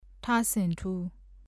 ထဆင်ထူး [tʰâ-sʰìɴdú ]子音字「ထ」の名前。